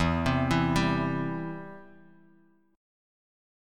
Em#5 chord